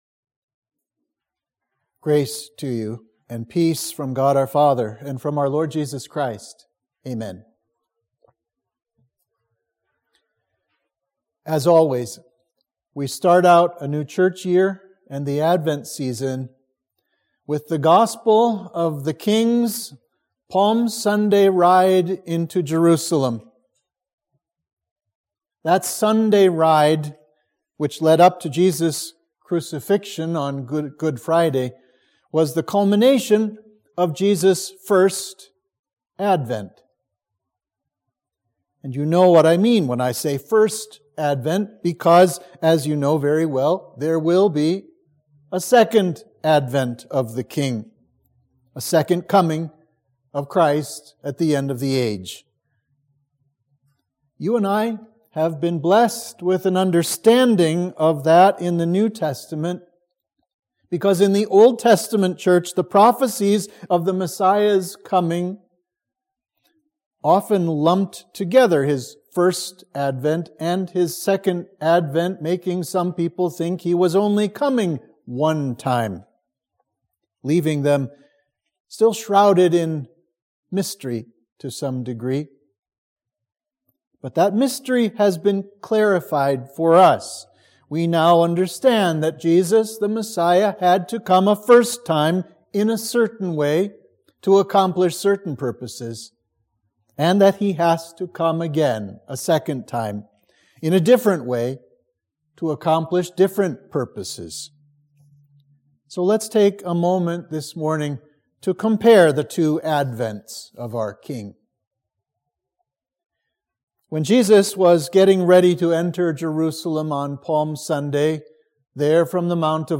Sermon for Advent 1